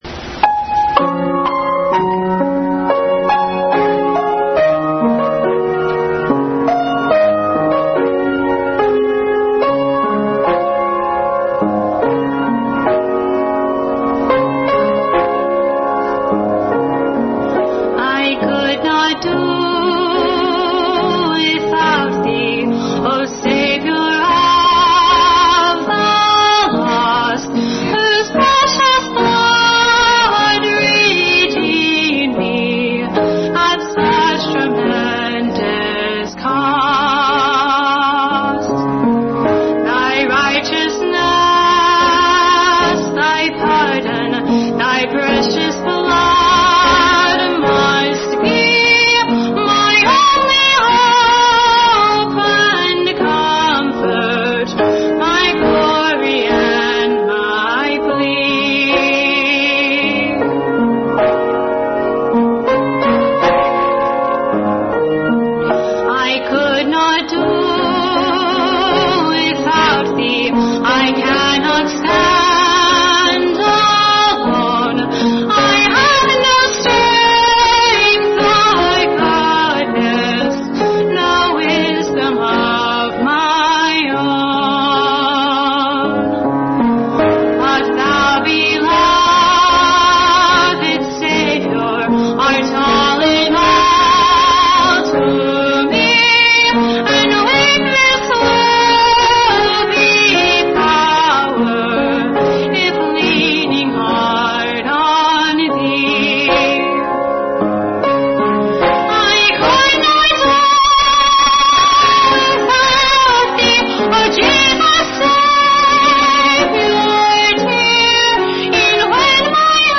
| Special music.